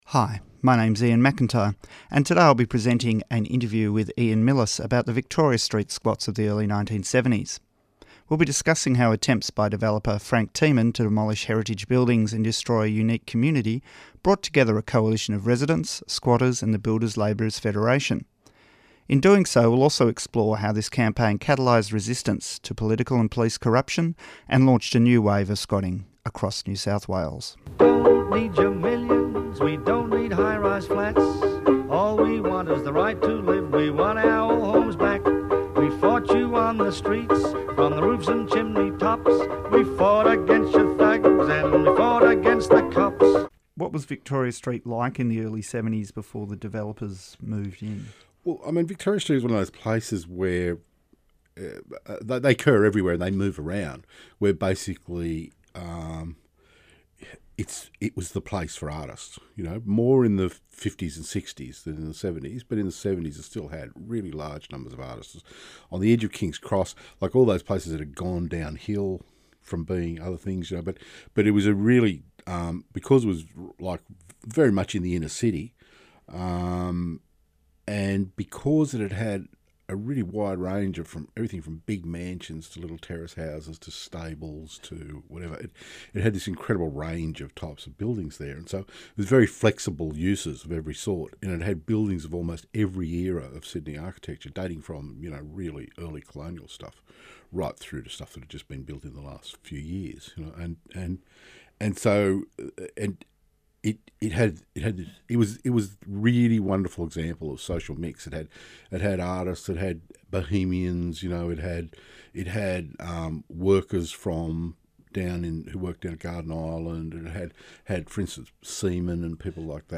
Victoria Street Squats Interview
Broadcast on Radio 3CR and podcast at the Australian Museum of Squatting and Community Audio